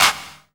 CLAP DM2.8.wav